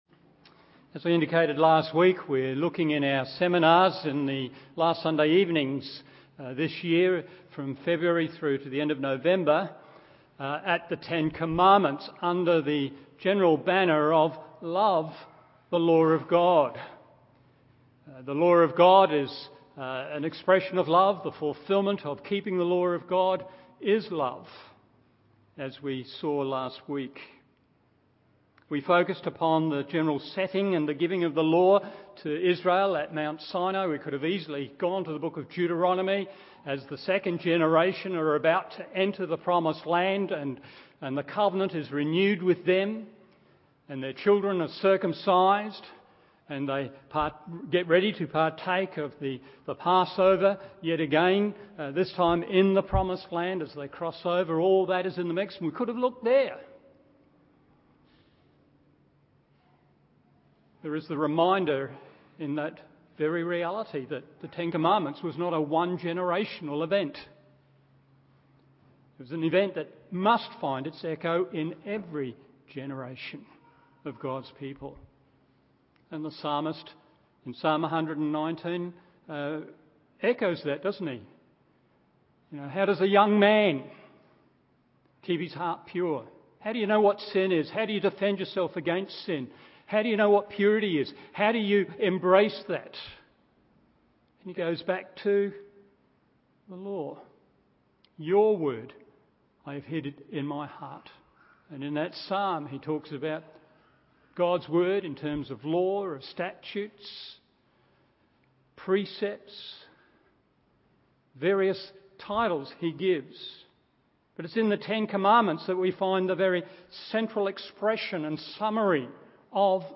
Morning Service Exodus 20:2-17 1. Know God and His Holiness it Reveals 2. Understand and Maintain its Symmetry 3. Look for its Inner-Scope not Loop-holes…